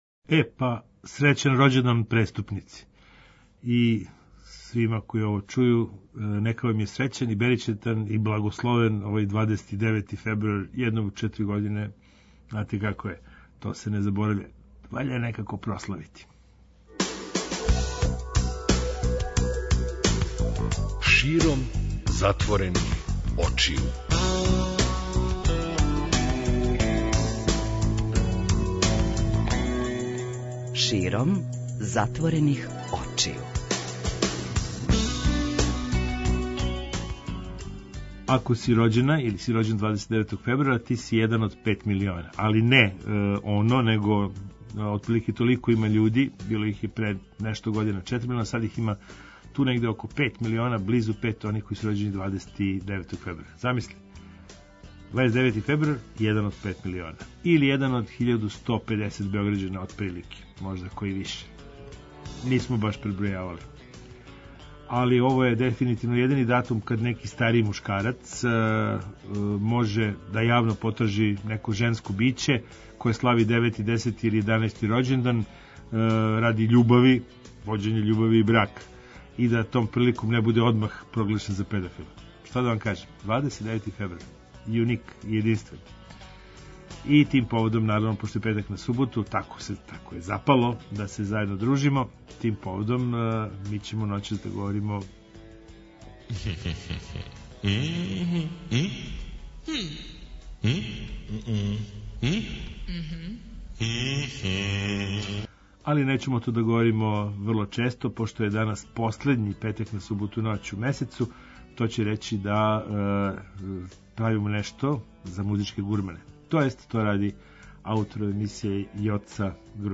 Музичка слагалица. Музички мозаик.